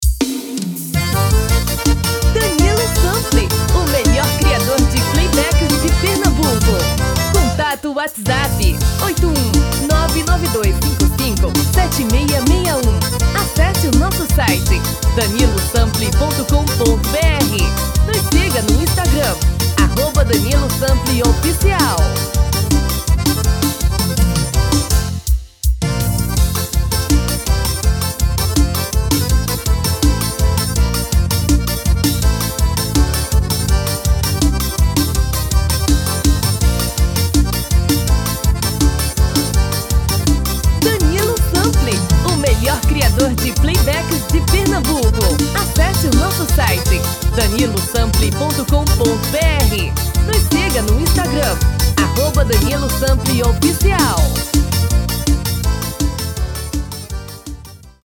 DEMO 1: tom original / DEMO 2: tom feminino